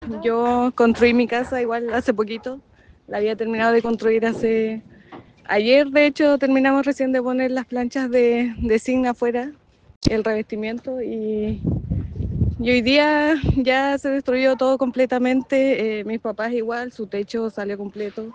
“Ayer, de hecho, terminamos de poner las planchas de zinc afuera, el revestimiento, y hoy día ya se destruyó todo completamente”, relató una de las vecinas afectadas por el hecho.